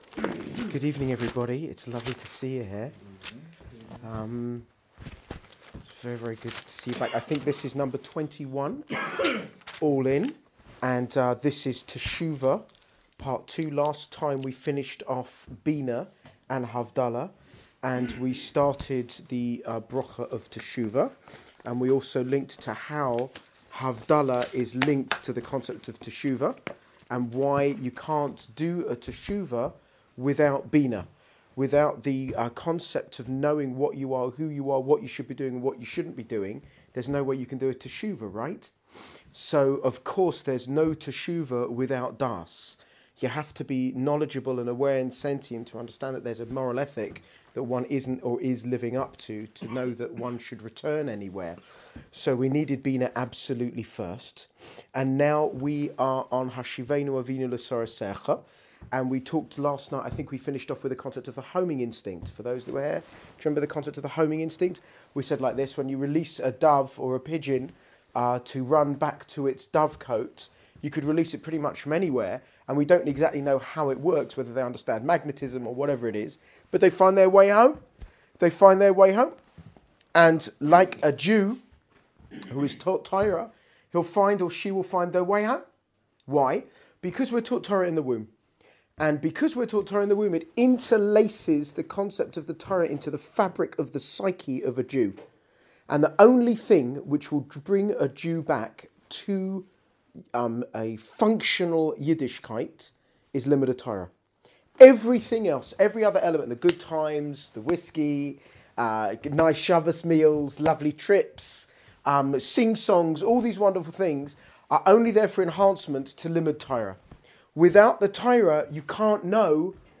Start Your Day The TorahWay Manchester provides daily shiurim on a wide range of topics.